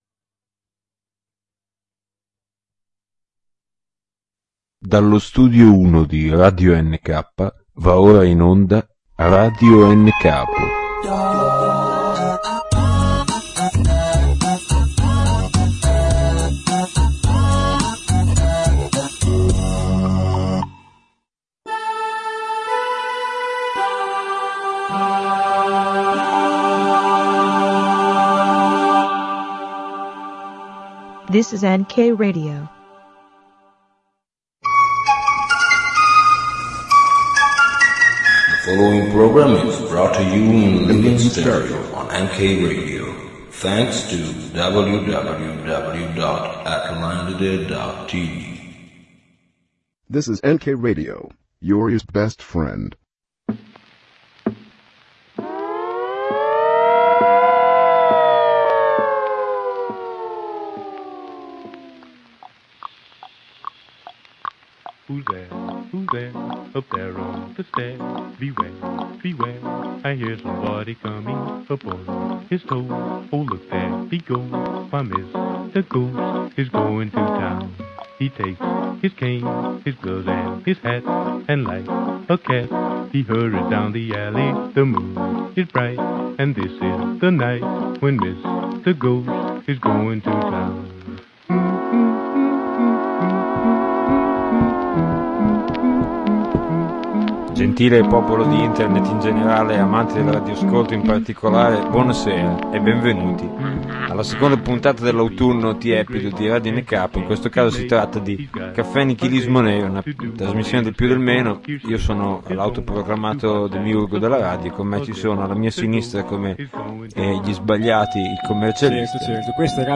In diretta dallo studio 1 di radio nk